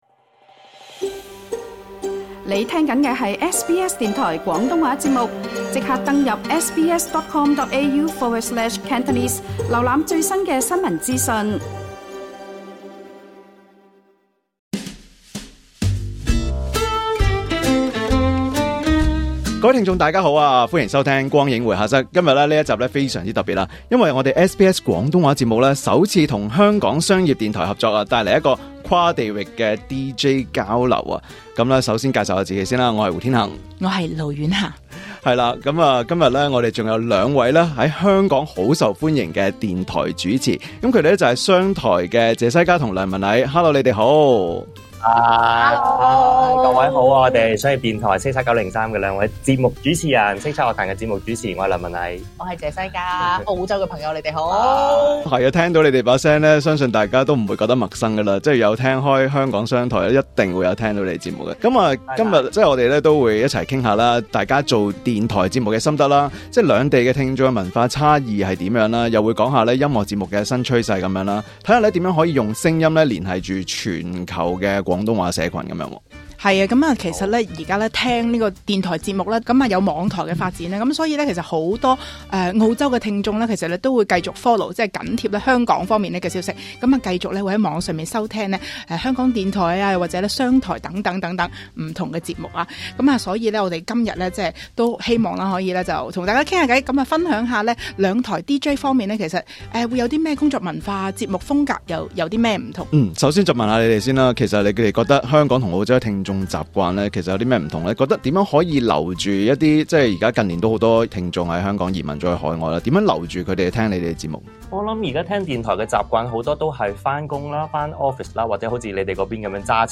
澳洲SBS廣東話首次與香港商業電台「叱咤903」攜手合作，兩地四位主持越洋用聲音分享創作及廣播新時代心得。